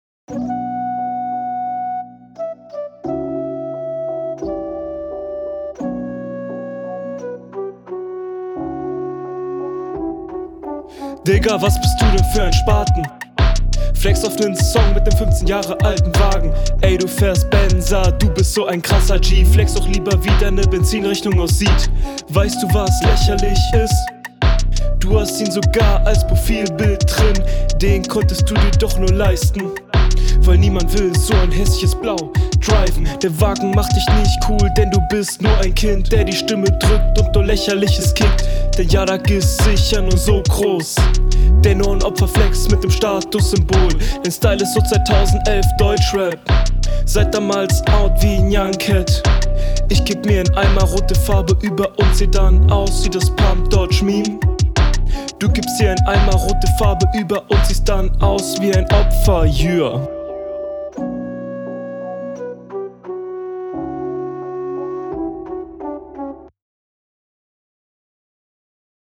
Ich mag deine Stimme ziemlich, das kommt was das angeht recht stylisch, ist flowlich allerdings …
Sound: Der Beat ist sehr gechillt.
Also, die Runde ansich ist Soundtechnisch relativ solide.